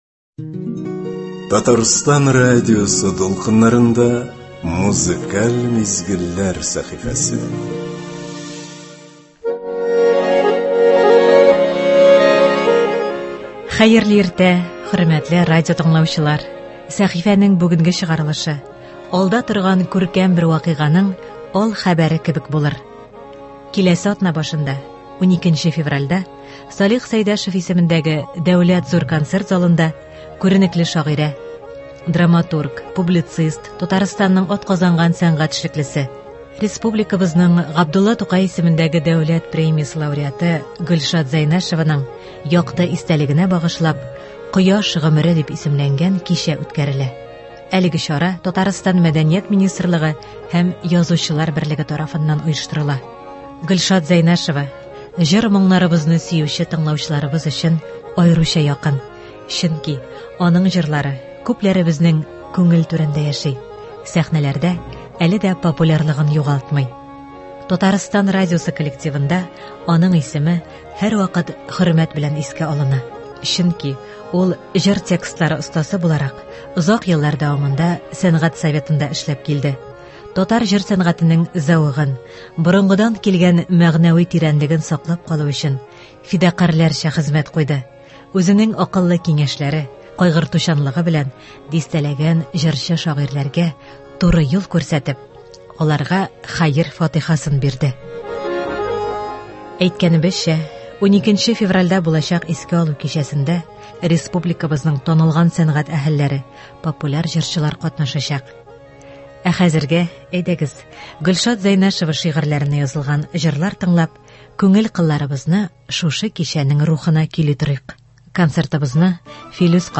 Шул уңайдан тыңлаучылар игътибарына шагыйрә шигырьләренә язылган җарлардан төзелгән концерт тәкъдим ителә.